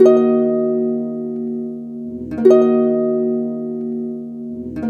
Hands Up - Harp.wav